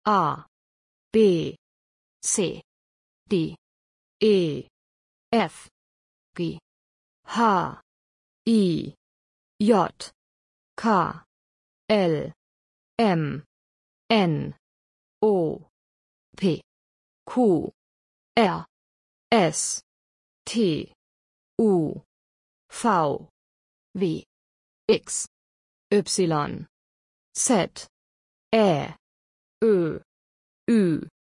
حروف الفبای آلمانی با تلفظ صوتی
Download-German-audio-alphabet.mp3